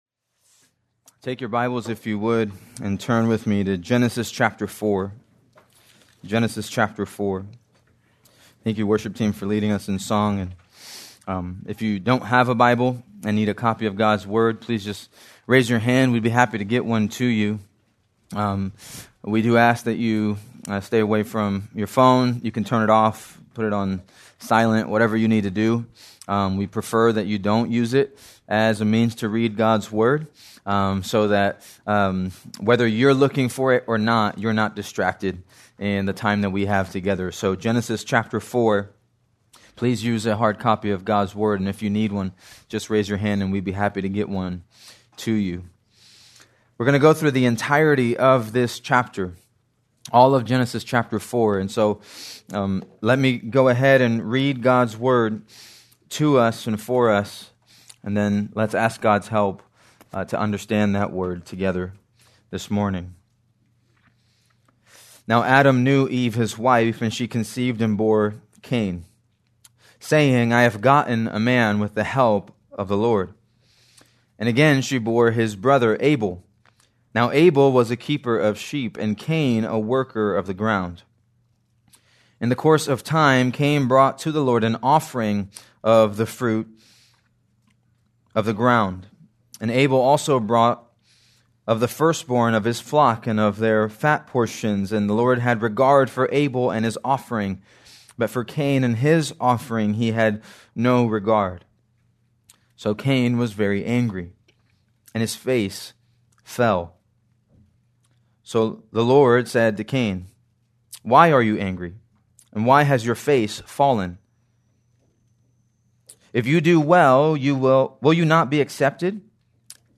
April 5, 2026 - Sermon